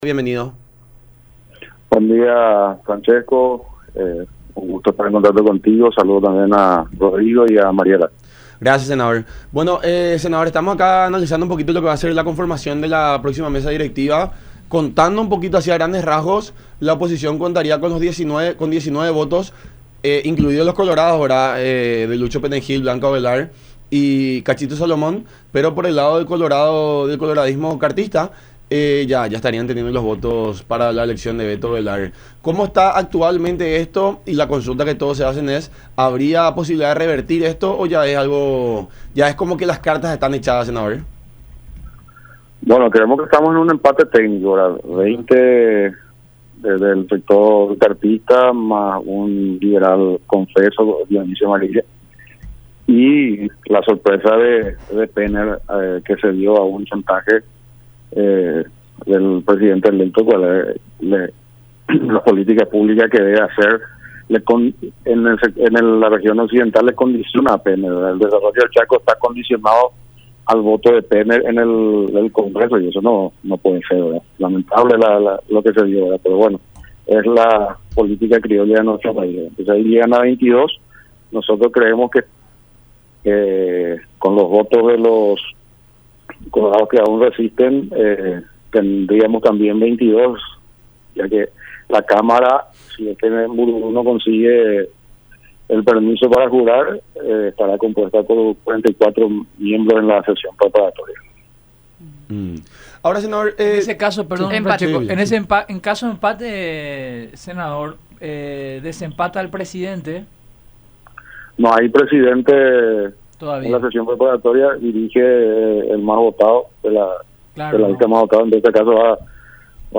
Es lamentable”, manifestó Villalba en charla con La Unión Hace La Fuerza a través de Unión TV y radio La Unión.